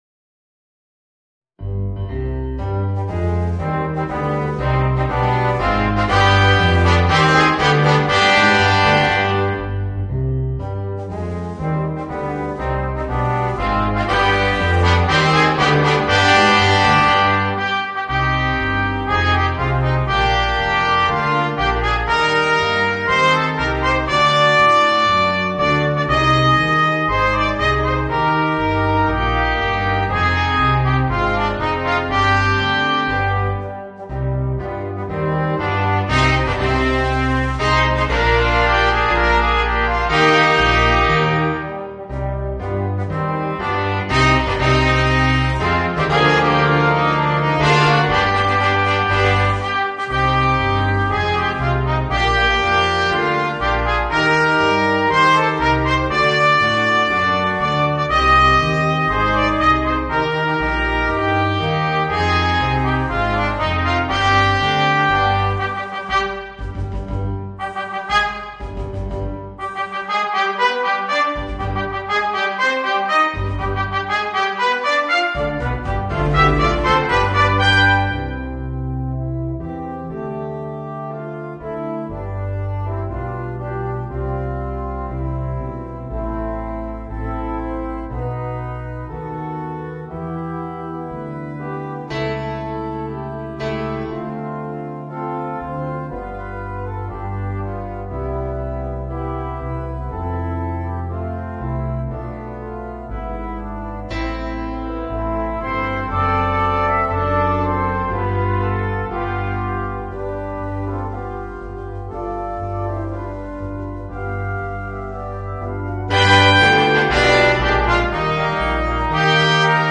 Voicing: 2 Trumpets, Trombone and Euphonium